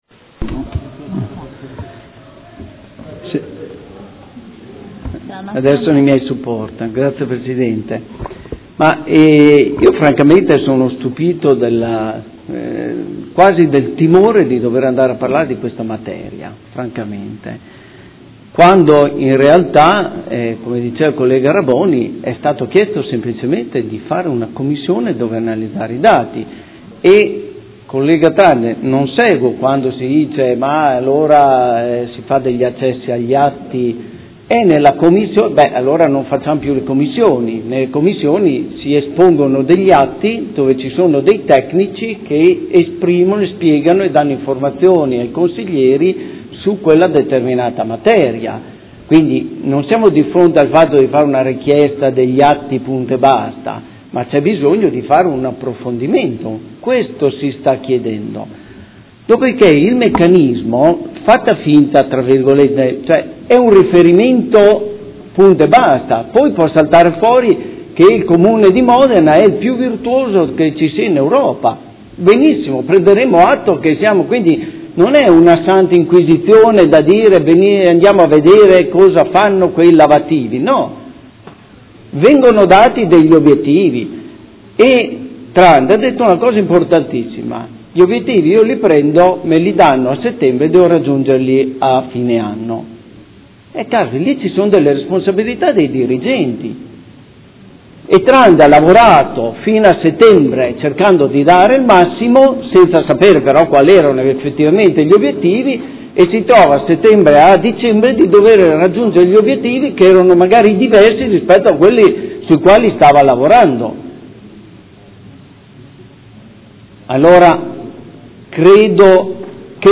Seduta del 14/04/2016 Dibattito. Ordine del Giorno presentato dal Consigliere Montanini del Gruppo Consiliare CambiAMOdena avente per oggetto: Criteri di erogazione della parte variabile dello stipendio dei dipendenti comunali